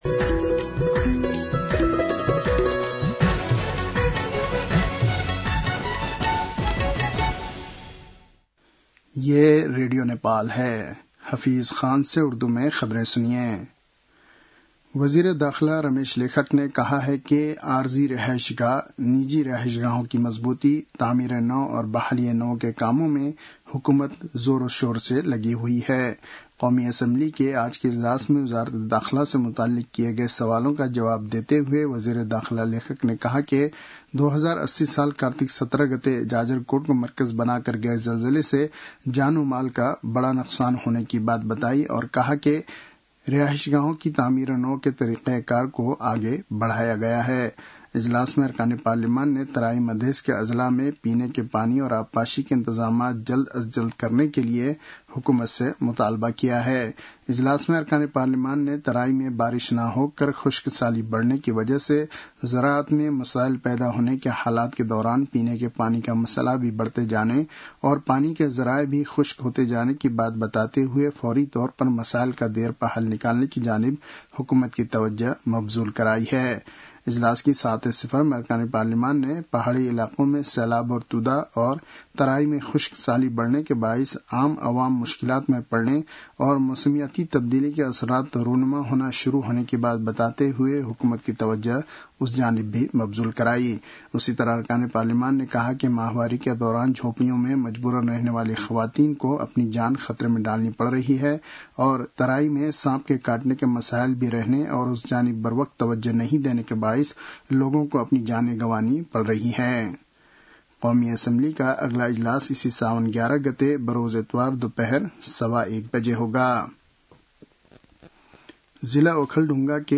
उर्दु भाषामा समाचार : ६ साउन , २०८२